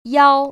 [yāo]